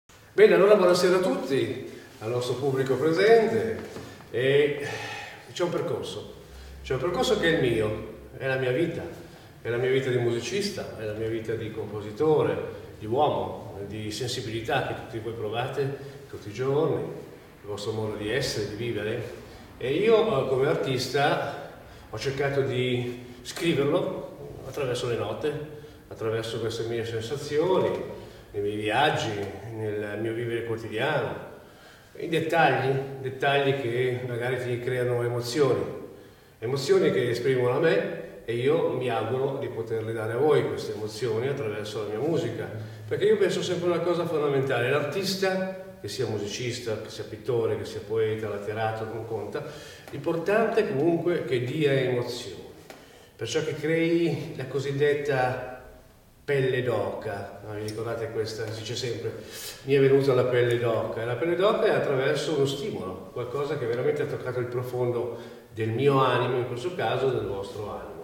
IV OTTOBRE MUSICALE A PALAZZO VALPERGA
pianista